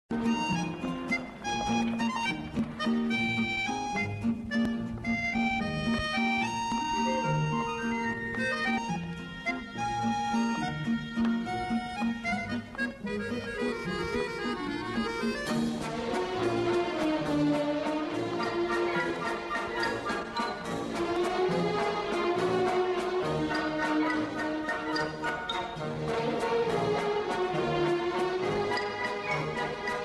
Просто исполнена в ритме вальса.